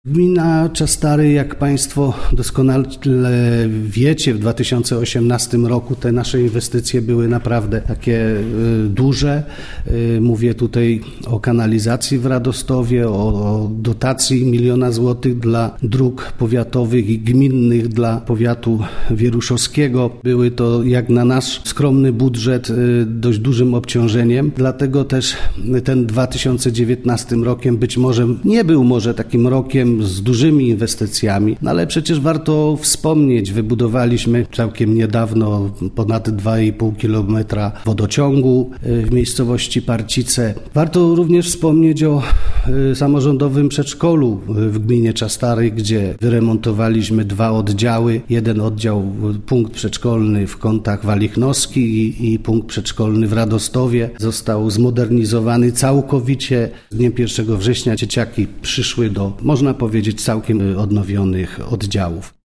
– mówił wójt gminy Czastary, Dariusz Rejman.